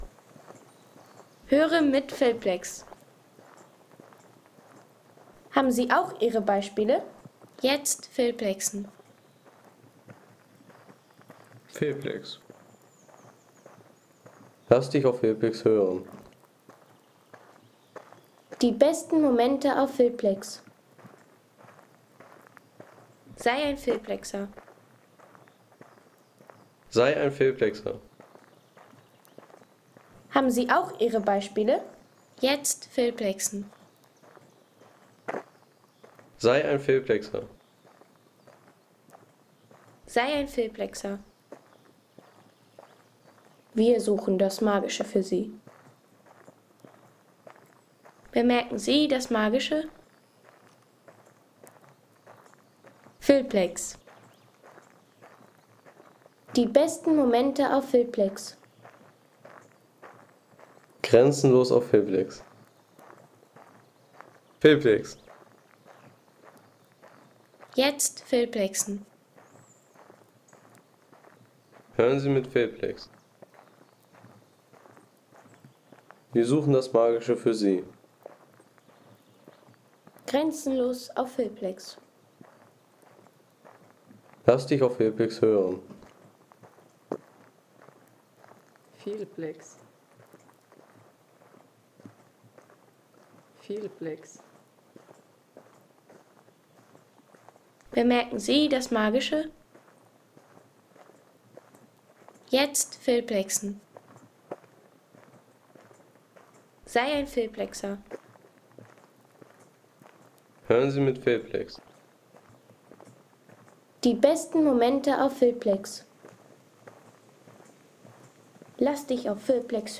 Spaziergang durch die Felsenlandschaft der Sächsischen Schweiz.